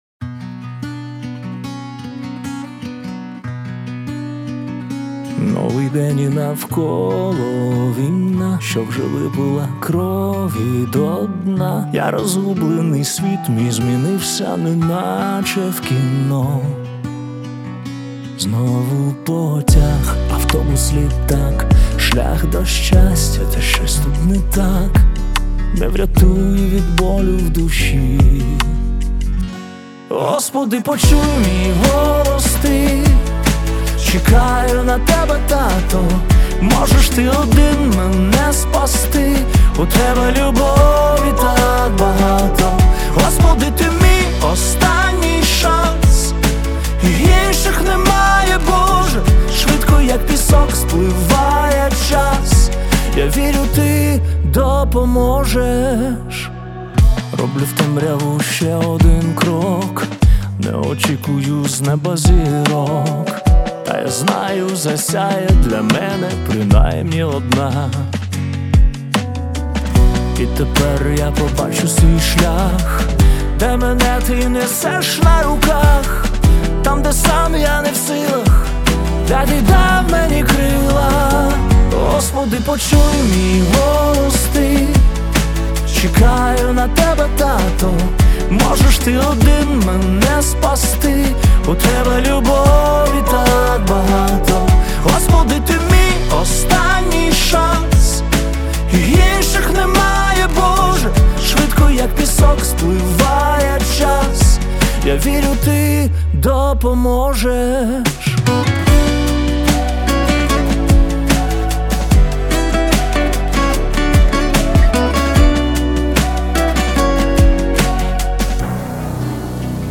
глибоку та емоційну композицію про віру